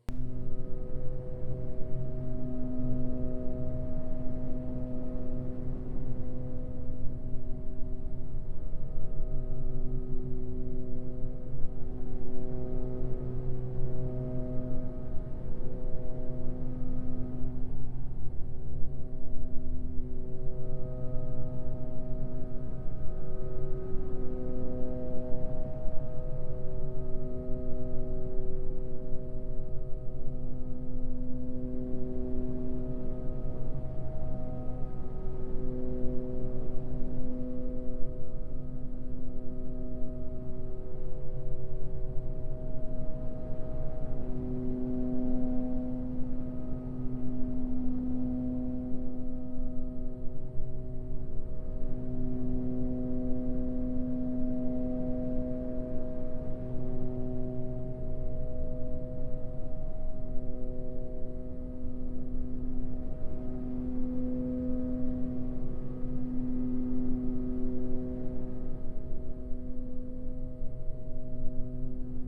HotelAmbience.wav